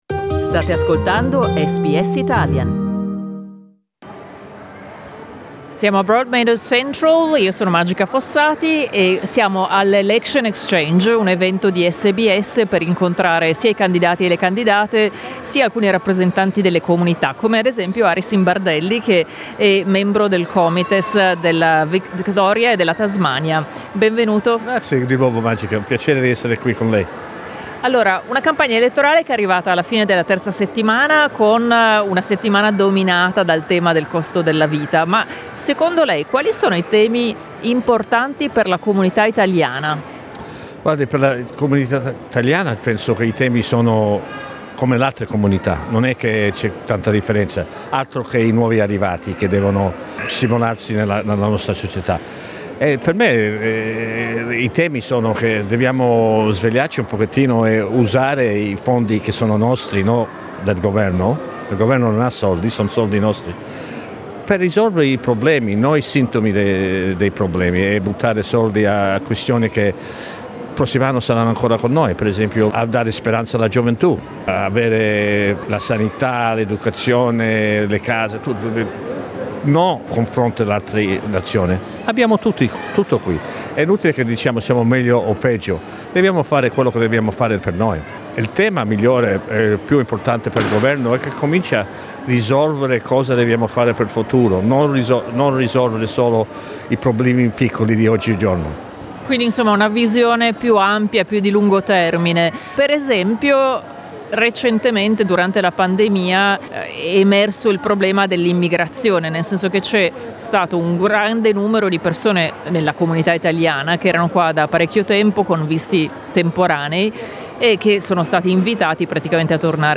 "Election Exchange" è un ciclo di incontri organizzato da SBS radio in varie città australiane, per incontrare candidate/i, esponenti delle organizzazioni comunitarie ed elettori/elettrici. Sabato 30 aprile SBS Radio ha tenuto uno degli incontri a Melbourne.